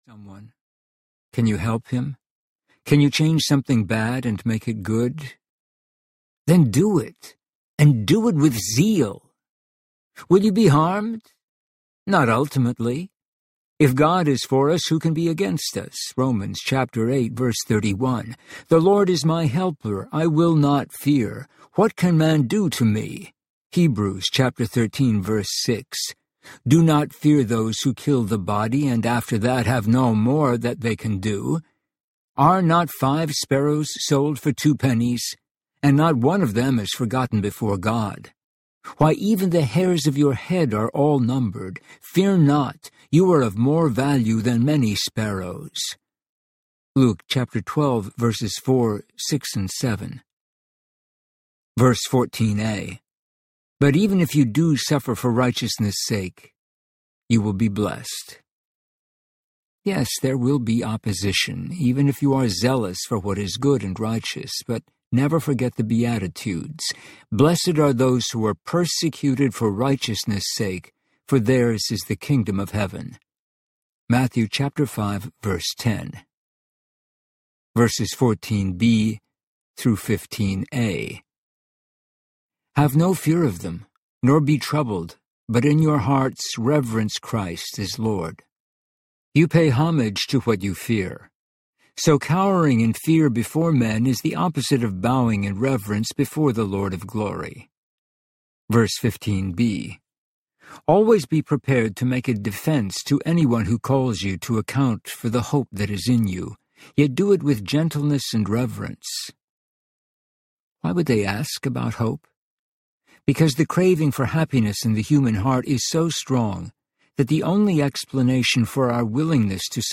A Godward Life Audiobook
Narrator
9.68 Hrs. – Unabridged